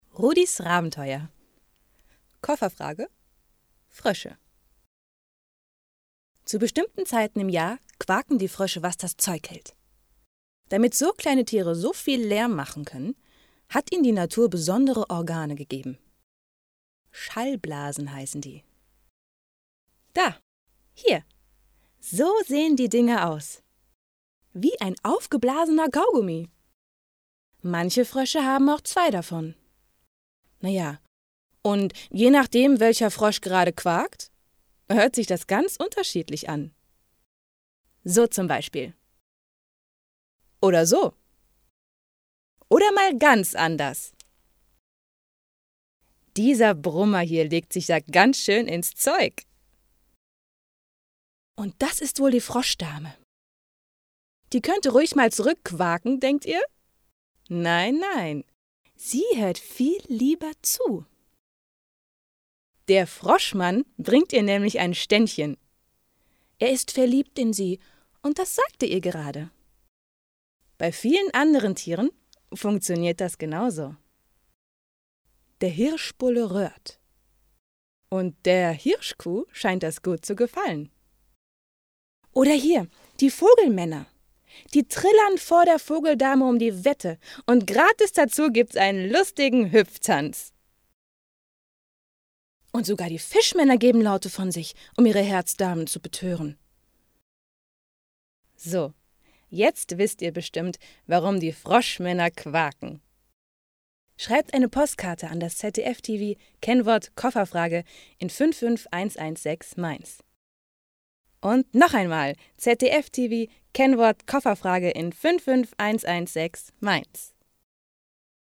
Junge Sprecherin deutsch
Sprechprobe: Werbung (Muttersprache):
Young German voice